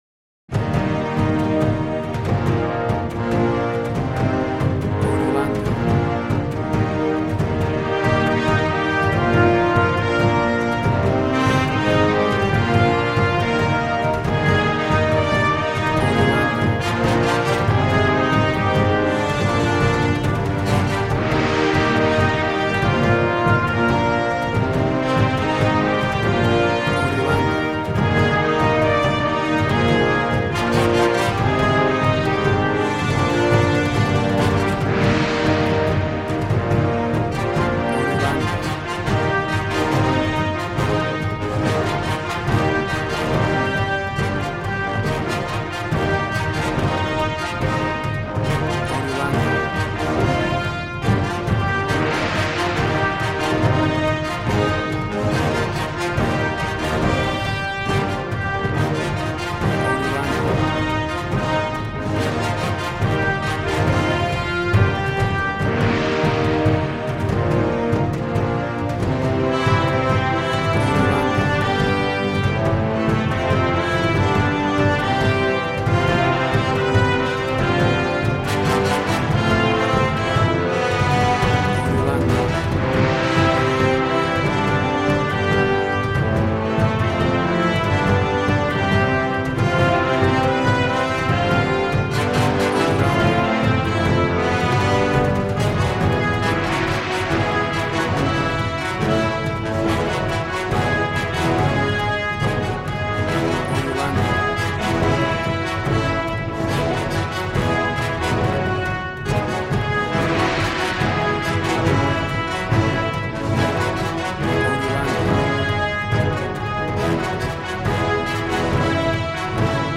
Action and Fantasy music for an epic dramatic world!
Tempo (BPM): 140